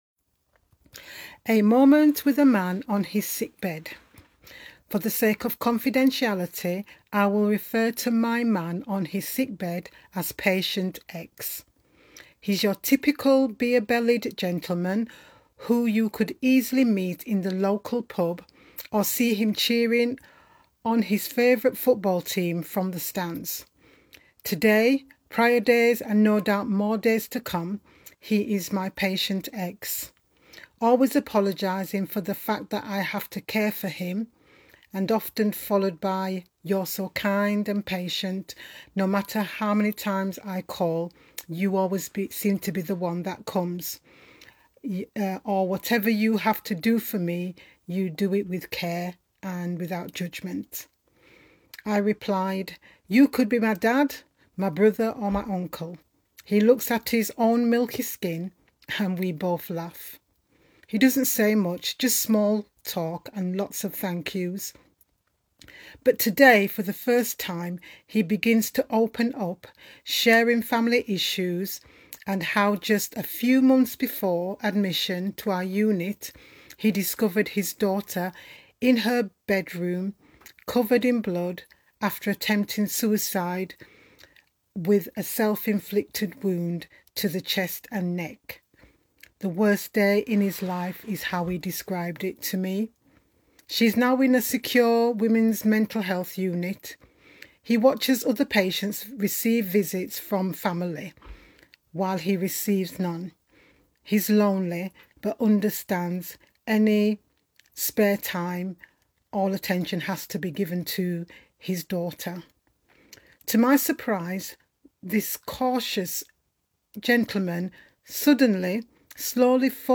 Today's Prayer